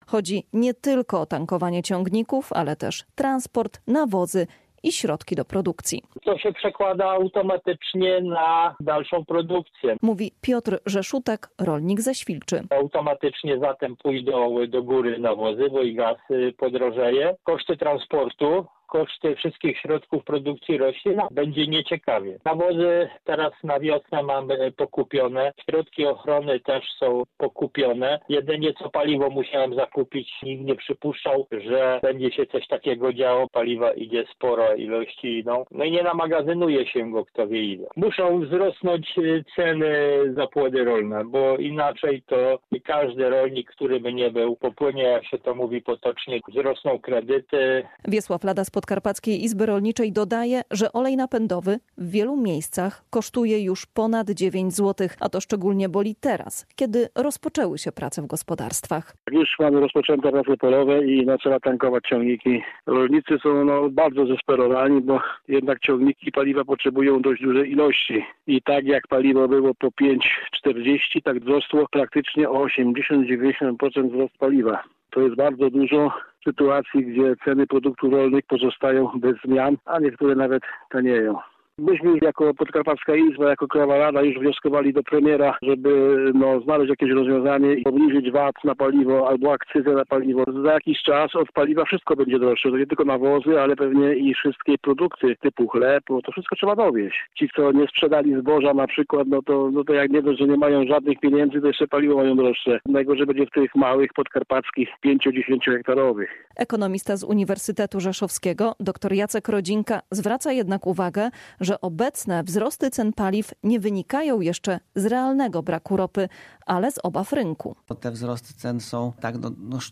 Relacje reporterskie • Wzrost cen paliw coraz mocniej uderza w rolników.